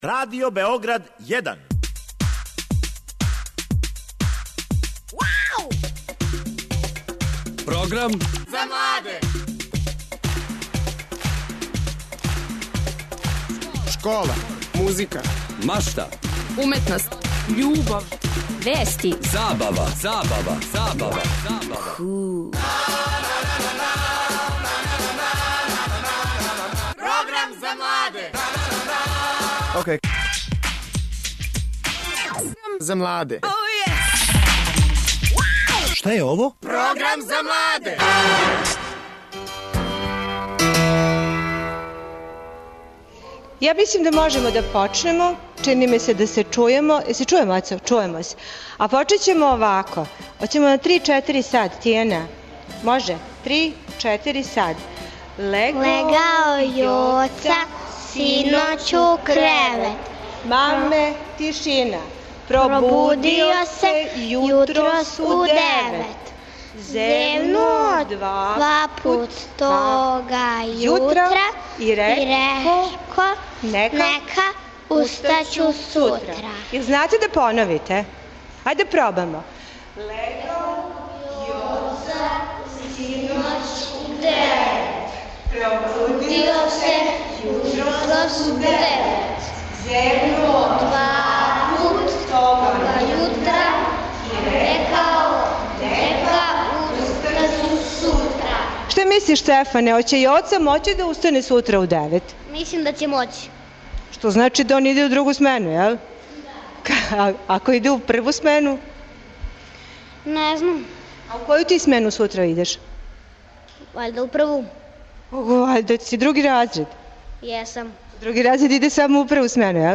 Нама није свеједно, и због тога вам поздрав за нови почетак шаљемо из нове школе!